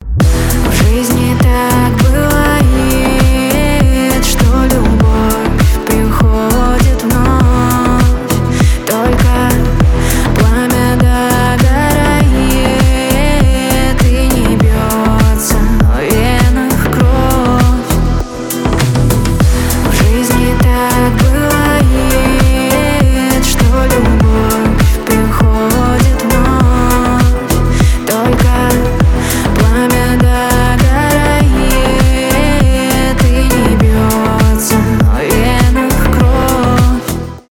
клубные
красивый женский голос , club house